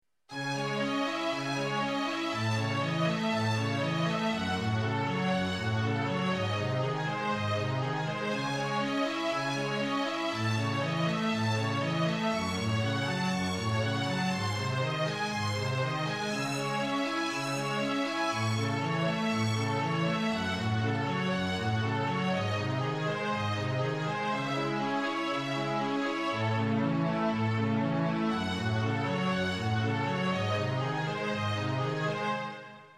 Takové útržky z klasiky.